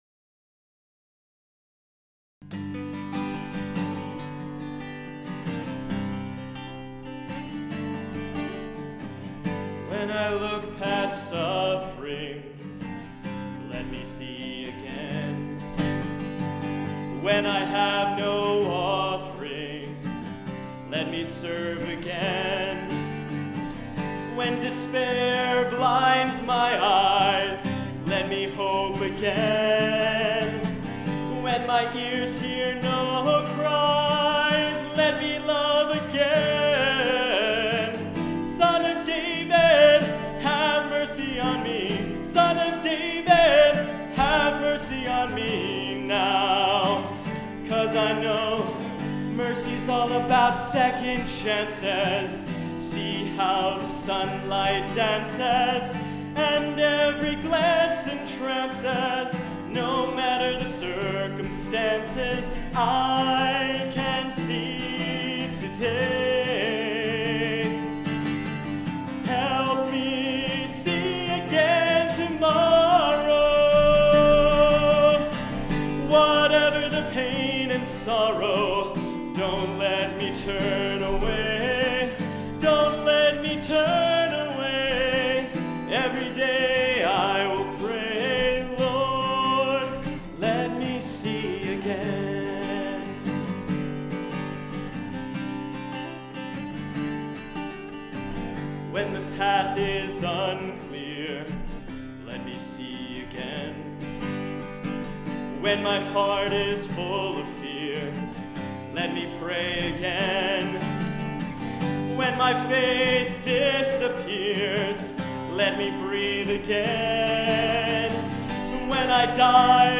second-chances-live.mp3